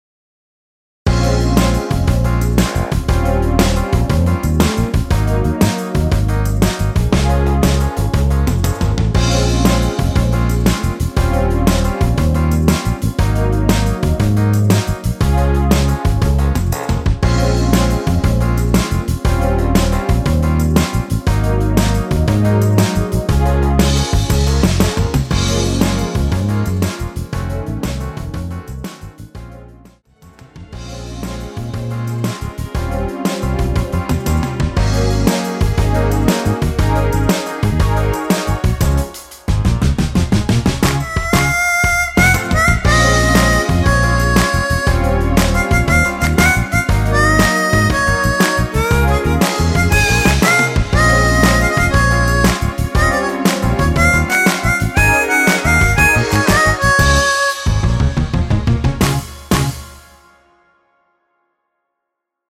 엔딩이 페이드 아웃이고 너무 길어서 노래 하시기 좋게 8마디로 편곡 하였습니다.(미리듣기 참조)
Bbm
앞부분30초, 뒷부분30초씩 편집해서 올려 드리고 있습니다.
중간에 음이 끈어지고 다시 나오는 이유는